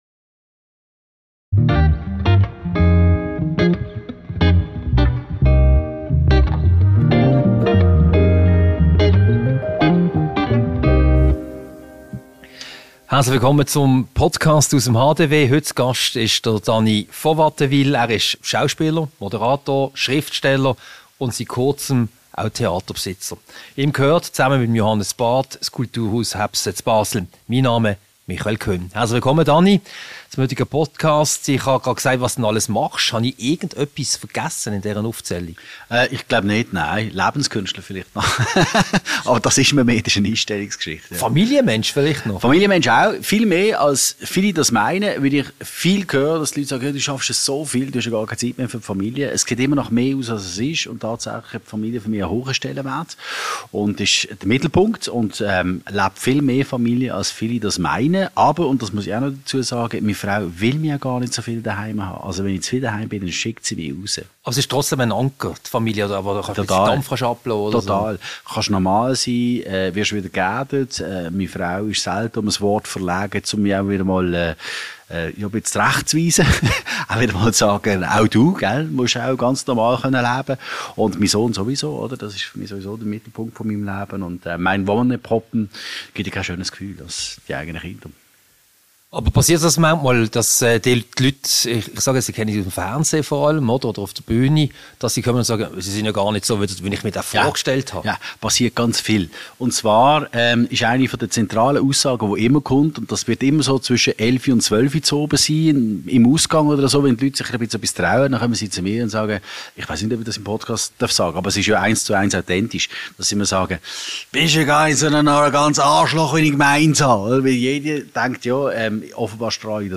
Ein Gespräch mit über Jobangebote aus Zürich, das Kulturhaus Häbse und ein paar persönliche Fragen.